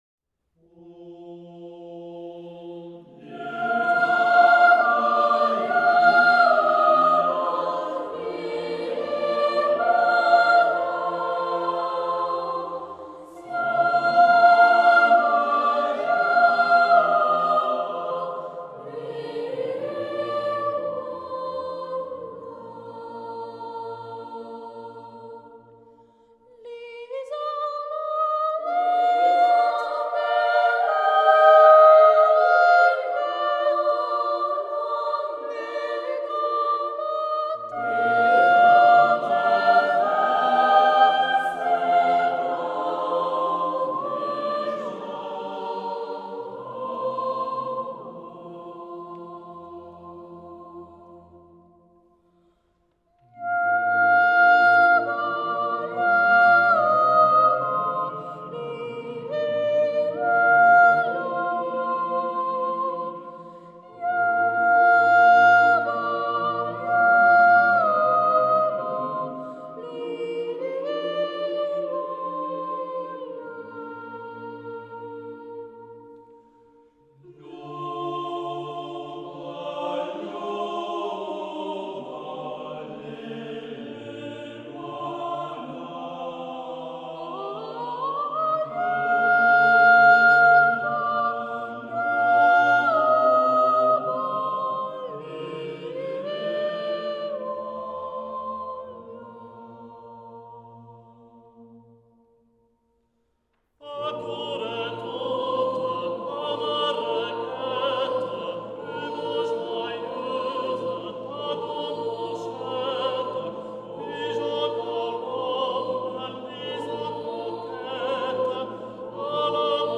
Zurich Boys’ Choir – The most beautiful Swiss folk songs and tunes (Vol. 2)
Trad./Paul-André Gaillard/Gustave Doret